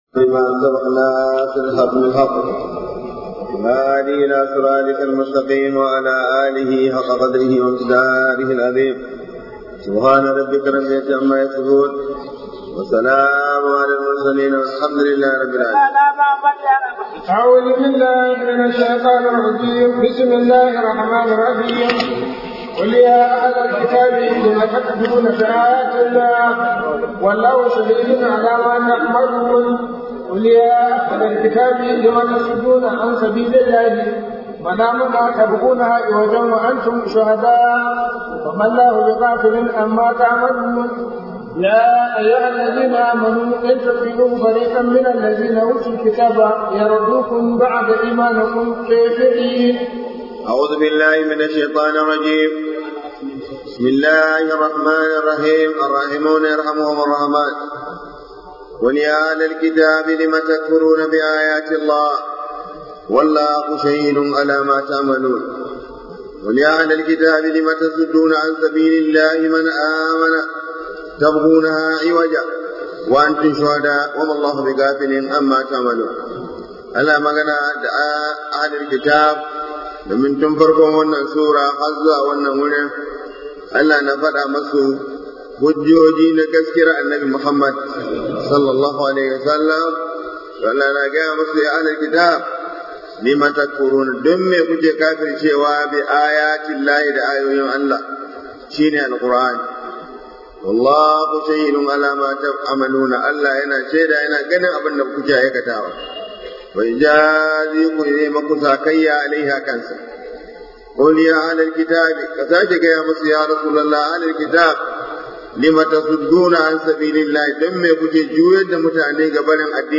0019 Tafsir SRT ALI IMRAN AYA